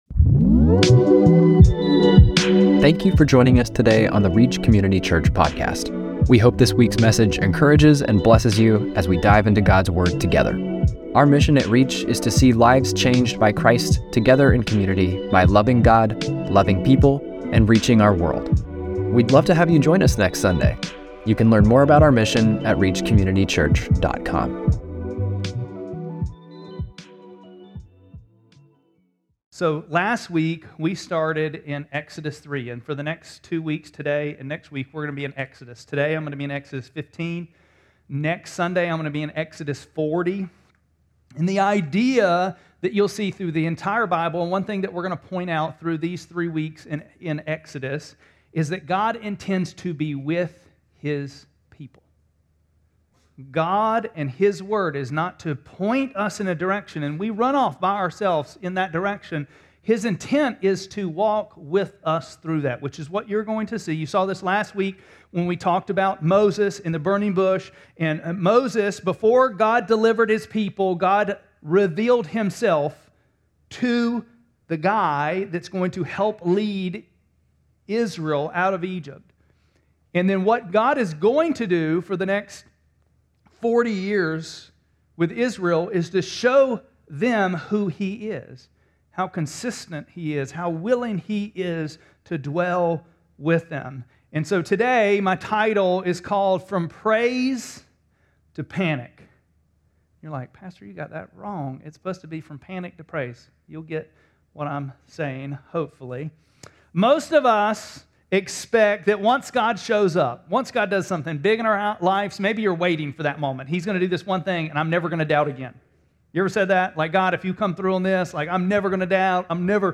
2-8-26-Sermon.mp3